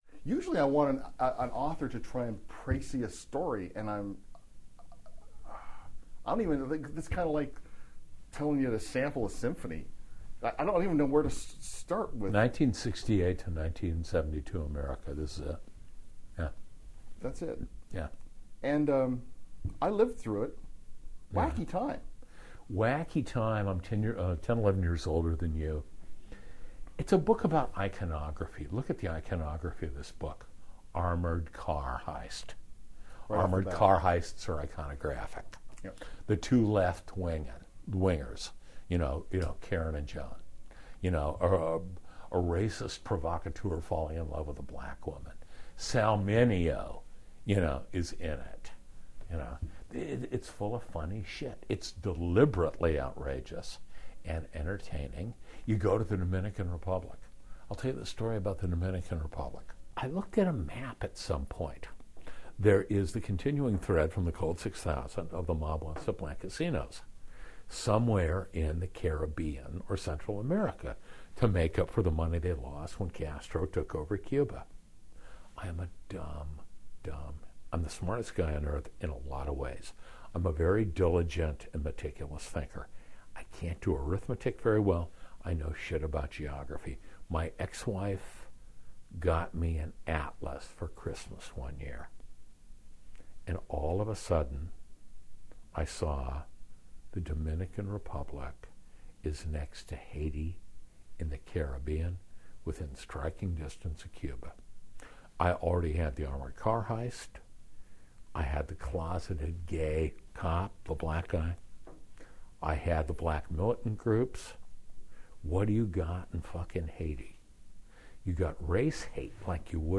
제임스 엘로이가 Bookbits 라디오에서 《Blood's A Rover》에 대해 이야기하는 모습